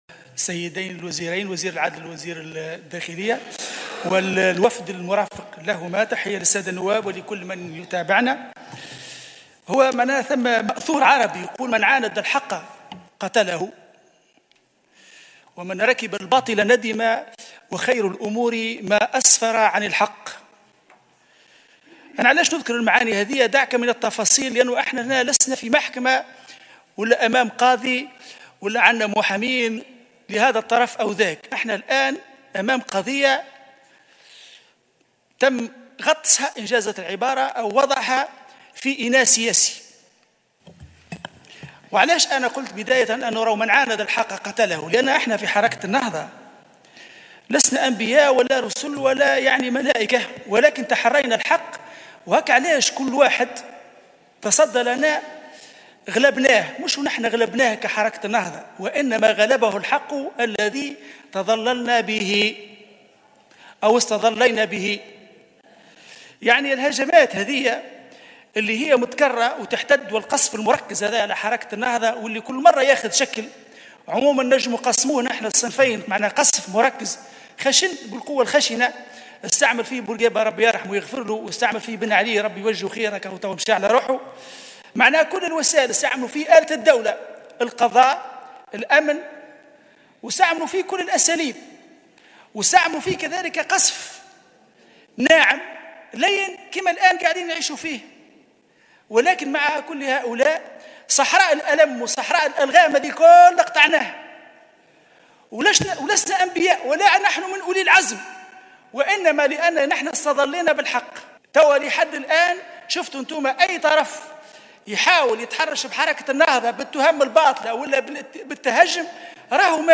قال النائب عن حركة النهضة محمد محسن السوداني في جلسة عامة لمساءلة وزيري العدل و الداخلية عن المعطيات التي كشفتها هيئة الدفاع عن الشهيدين إن" من عاند الحق قتله ومن ركب الباطل ندم" .